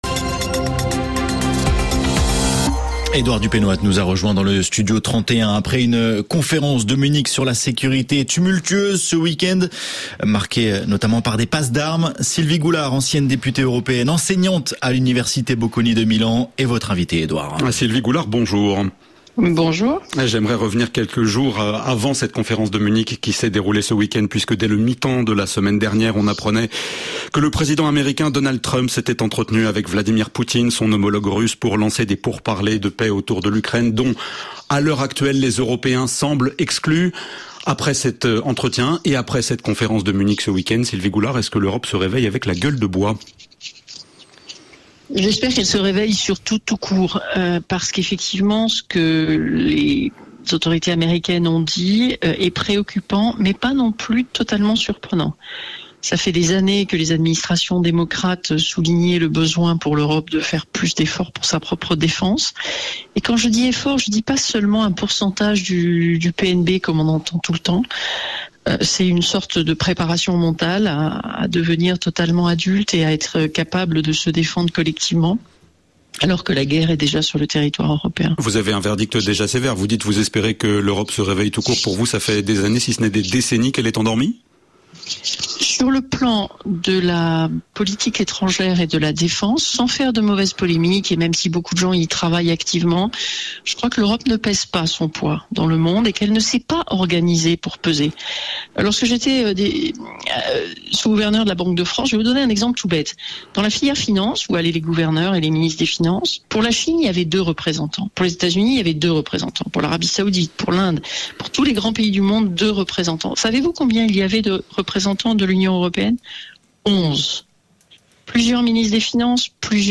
Sylvie Goulard, enseignante à l’université Bocconi de Milan en Italie et ancienne députée européenne, membre du Conseil de la fondation de la Conférence de Munich sur la sécurité, est ce lundi 17 février, la grande invitée internationale de la matinale.